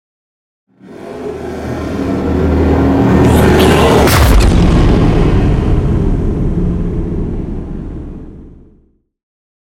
Dramatic electronic whoosh to hit trailer 481
Sound Effects
Epic / Action
Fast paced
In-crescendo
Atonal
futuristic
intense
tension